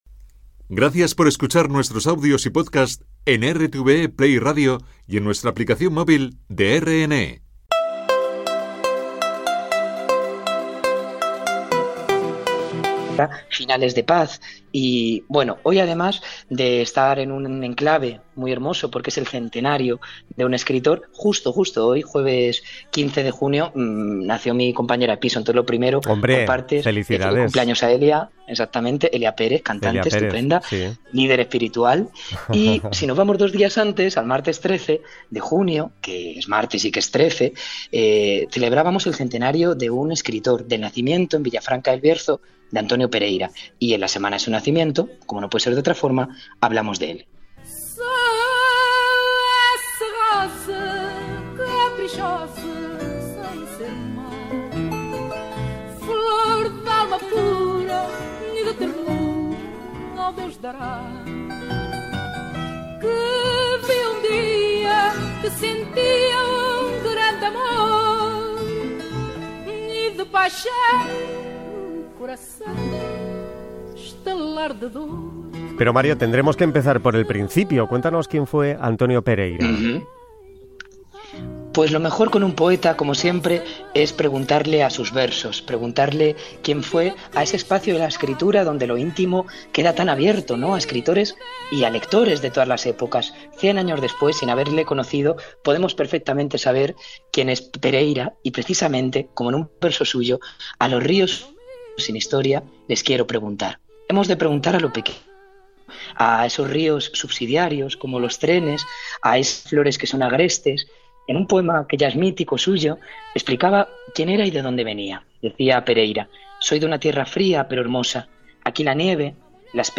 Programa emitido por RNE